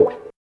95 GTR 3  -R.wav